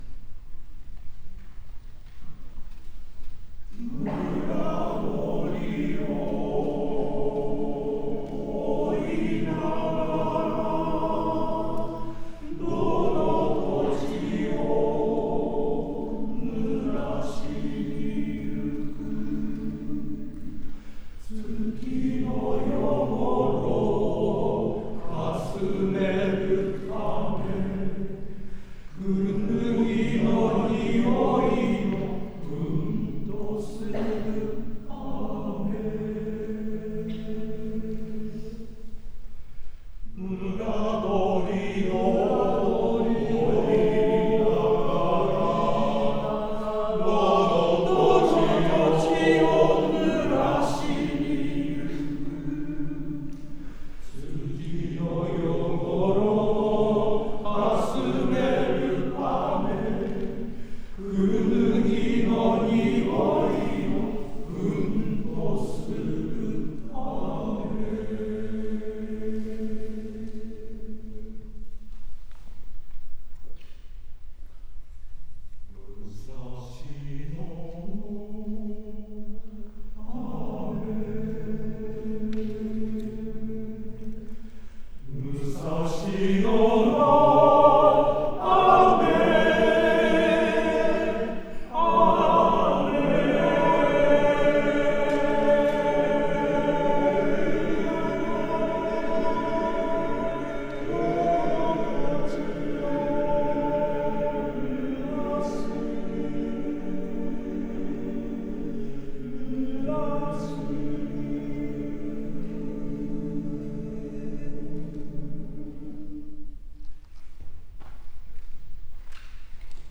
コーラスフェスティバル
会場 世田谷区民会館